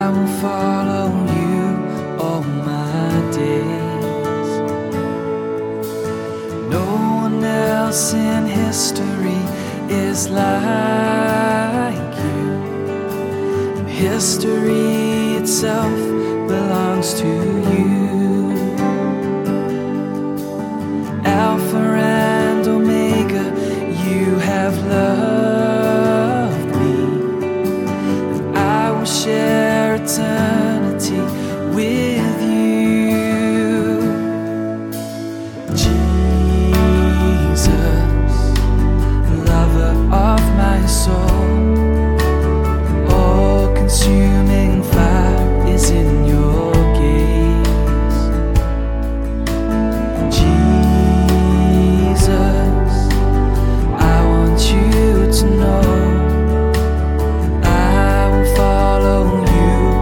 neuen Anbetungslieder
• Sachgebiet: Praise & Worship